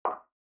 click-3.mp3